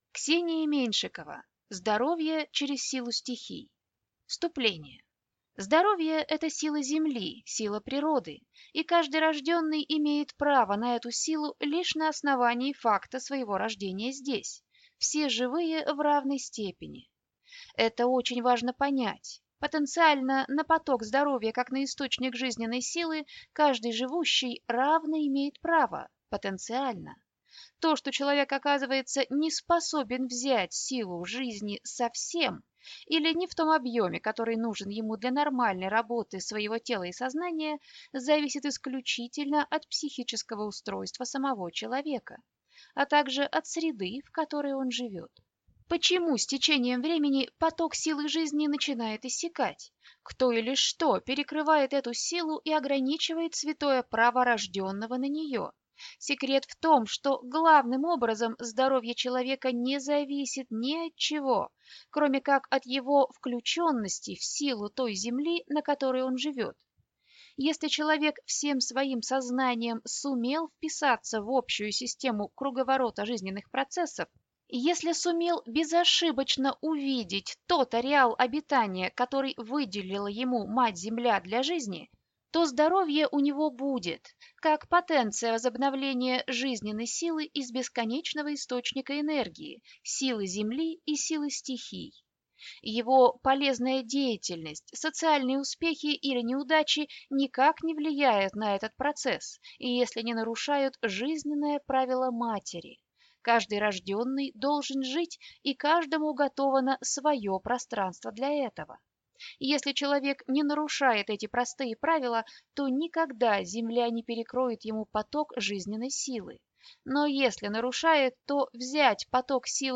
Аудиокнига Здоровье через силу стихий | Библиотека аудиокниг